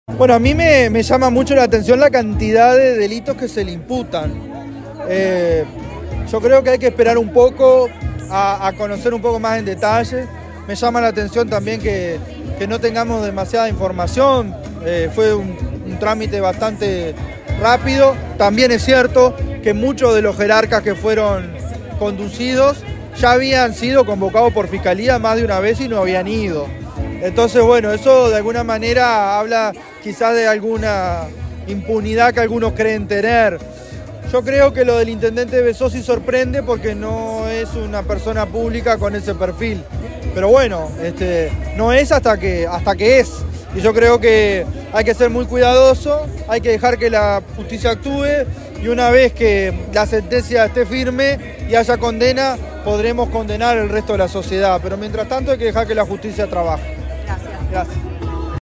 El senador Nicolás Viera y candidato a la Intendencia se refirió además en rueda de prensa a la crisis política que se vive en Soriano con la imputación del Intendente Bezzosi y su círculo de asesores.